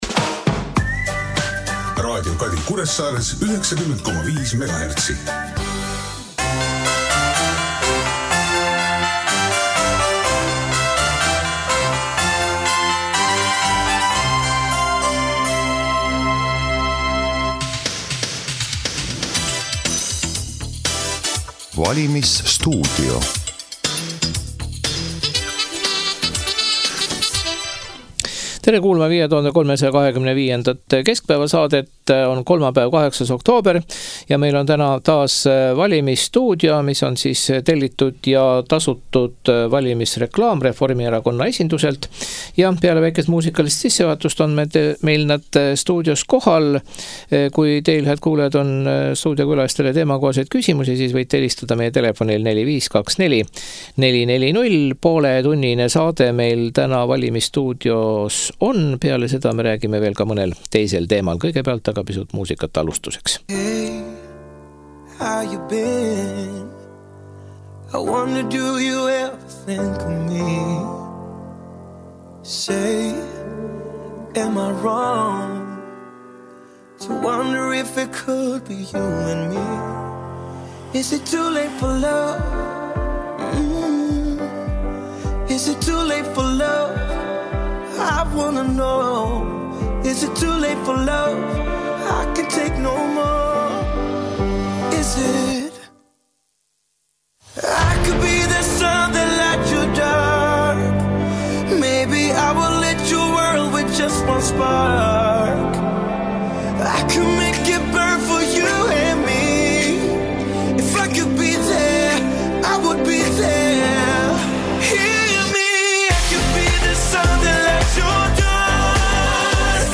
Valimisstuudio. Reformierakonna tellitud saade.